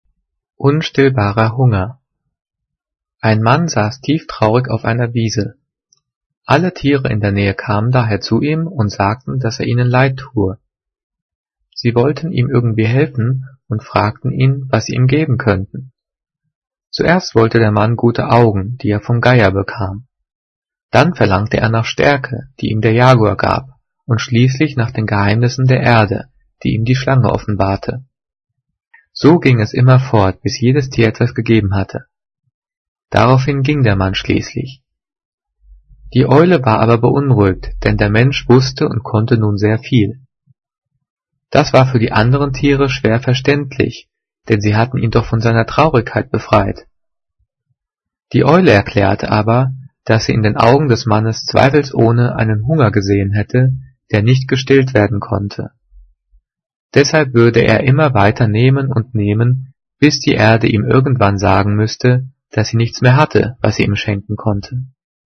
Diktat: "Unstillbarer Hunger" - 5./6. Klasse - Getrennt- und Zus.
Gelesen: